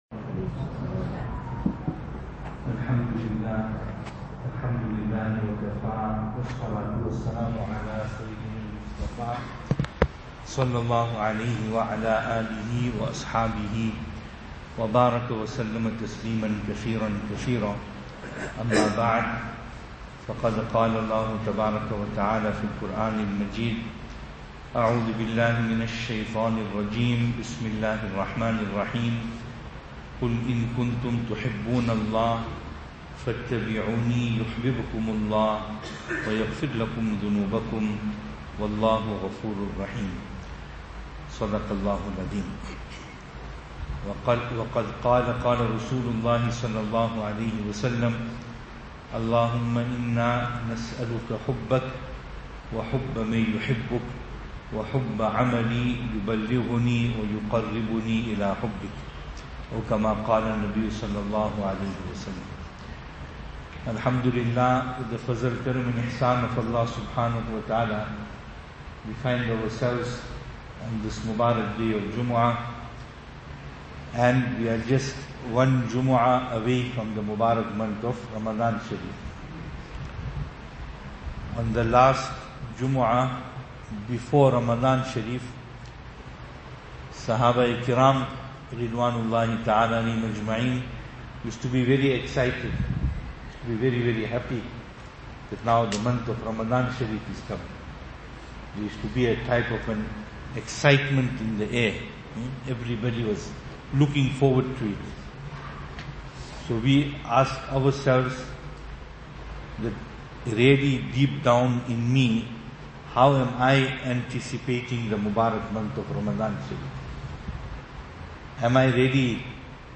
Jummuah bayaan Musjid Siraatul Jannah Ormonde